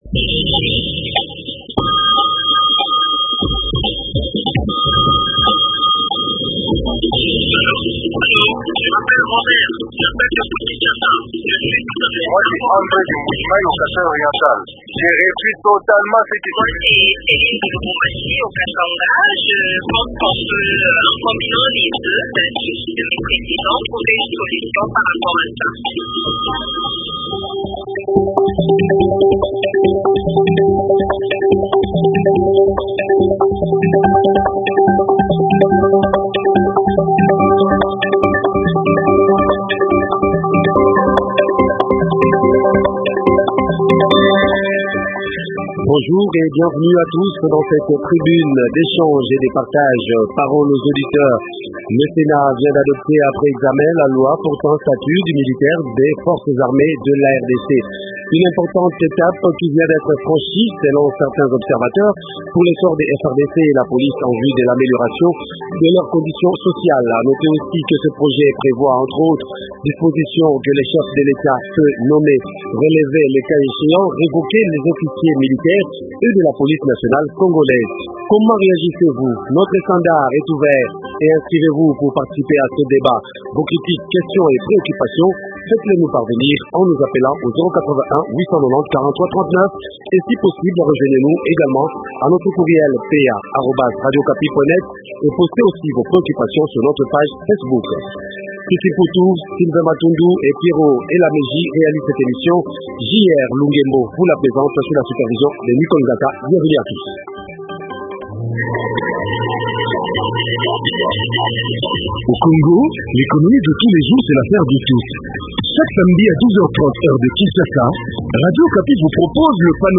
Invité: Eve bazaiba, deputée nationale Fichier audio : téléchargez Flash pour écouter.